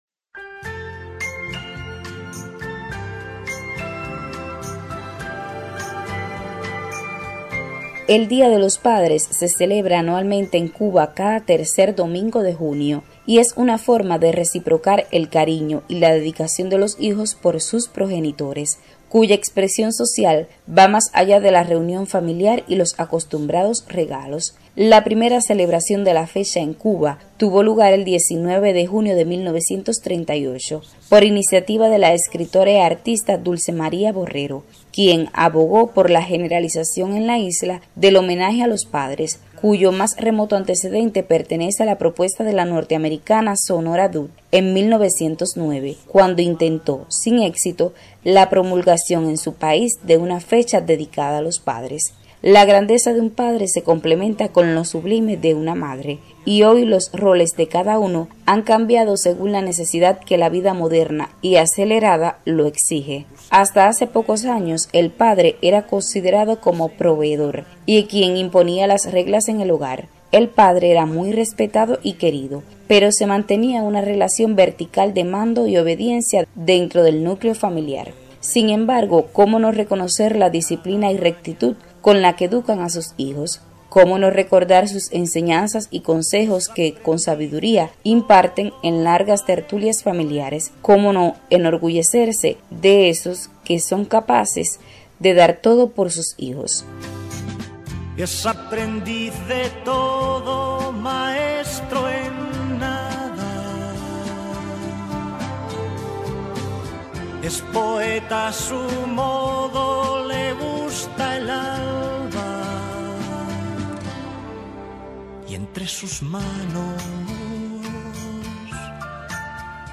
cronica_dia_padre.mp3